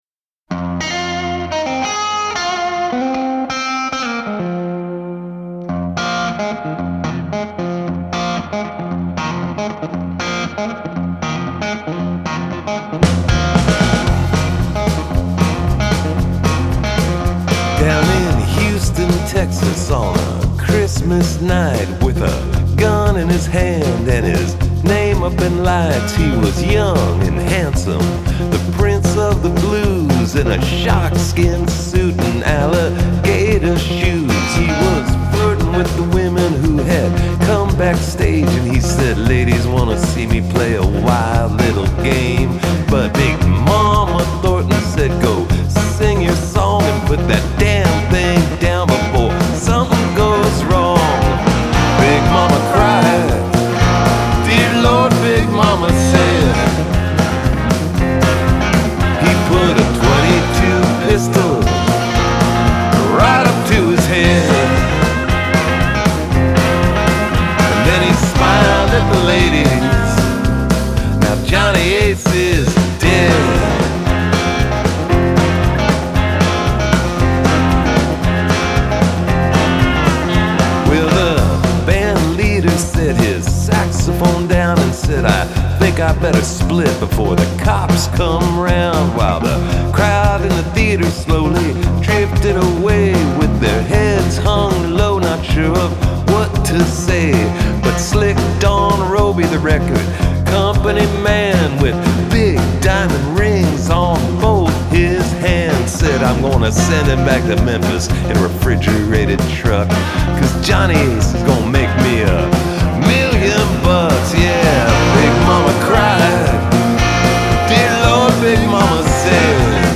Жанр: Blues Rock, Country Rock